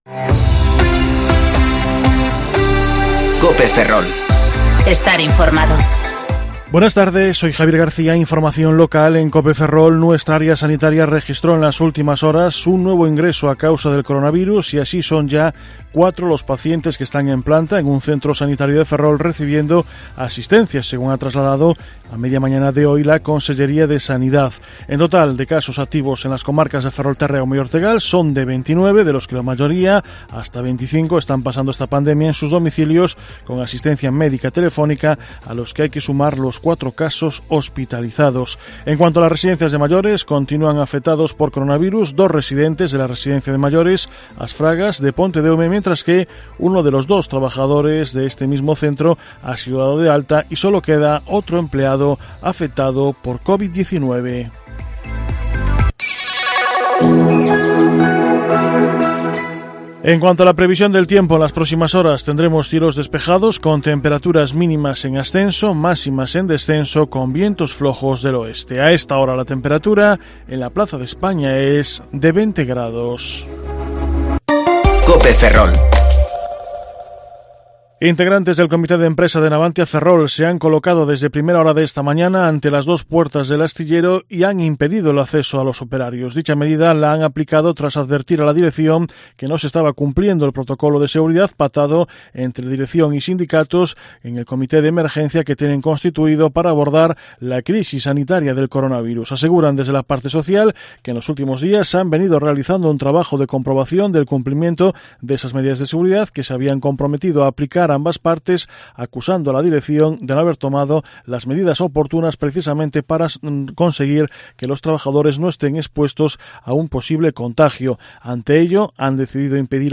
Informativo Mediodía COPE Ferrol - 20/5/2020 (De 14,20 a 14,30 horas)